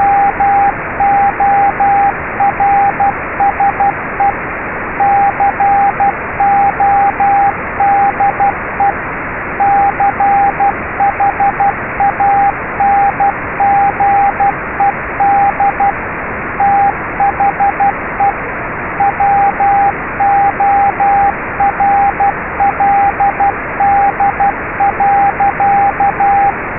Omdat we alleen lage tonen tot zo'n 1500 Hz gebruiken, is de sample rate van de WAV files laag gehouden, 4800 samples/sec. Daardoor zijn de WAV files redelijk klein en wordt de toonhoogte van de ruis beperkt tot een SSB bandbreedte.
Morse en ruis